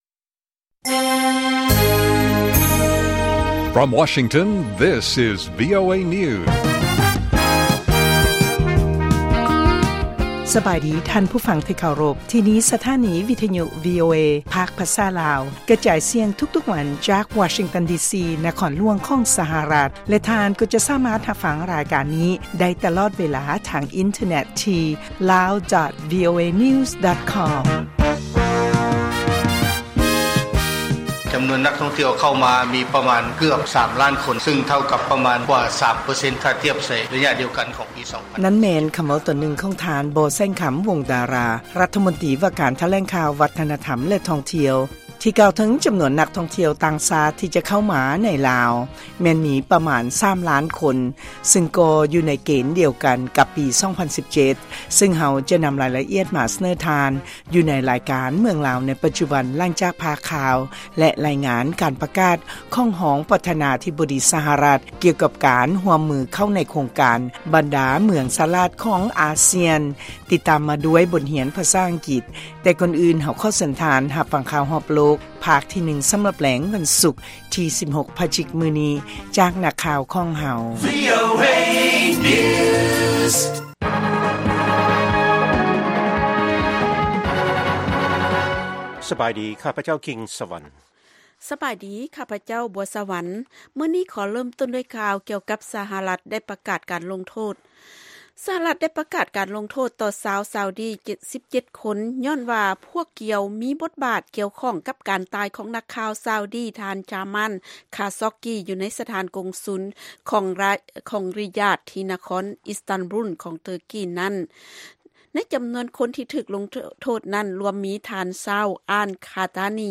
ລາຍການກະຈາຍສຽງຂອງວີໂອເອ ລາວ